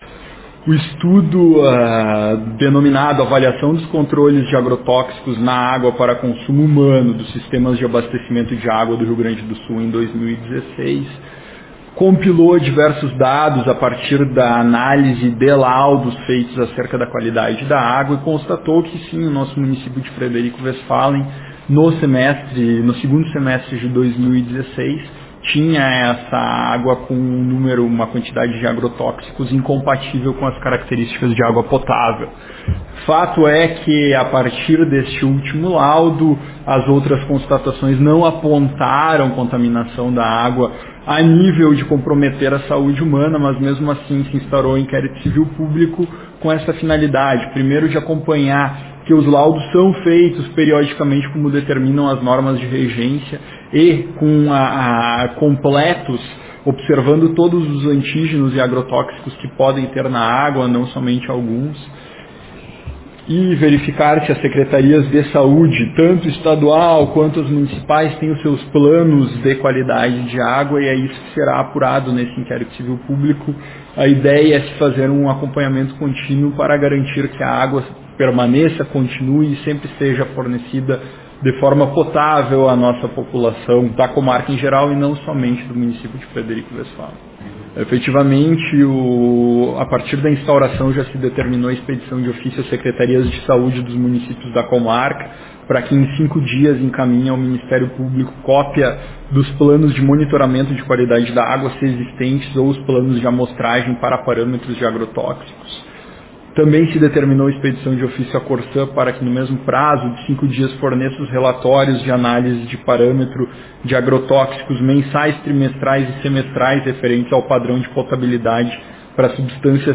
O promotor falou sobre os objetivos da instauração do inquérito e destacou que o acompanhamento da potabilidade da água deve ser contínuo em todos os municípios:
Promotor-João-Pedro.mp3